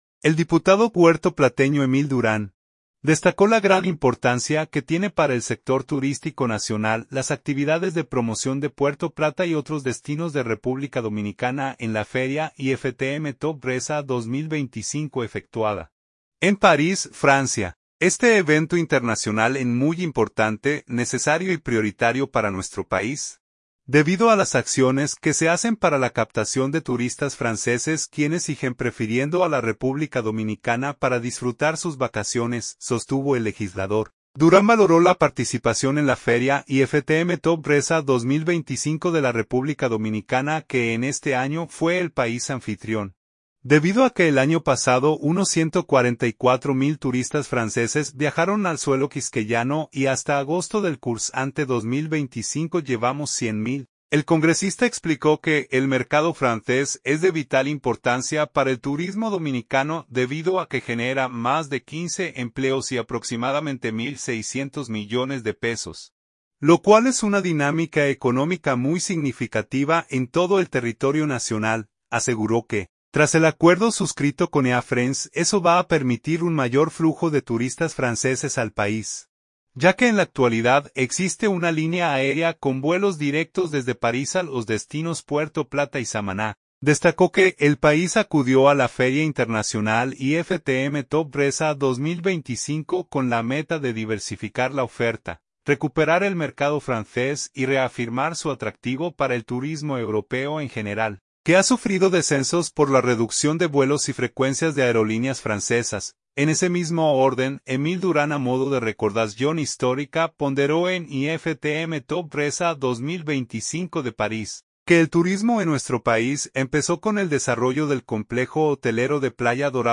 Se destaca que el diputado puertoplateño perteneciente al oficialista Partido Revolucionario Moderno (PRM), ofreció sus declaraciones al ser abordado por reporteros que dieron cobertura en Francia a la feria internacional de turismo IFTM Top Résa 2025, evento desarrollado desde el martes 23 al jueves 25 de septiembre en el París Expo Porte de Versailles.